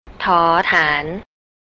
thor tharn
table (high tone)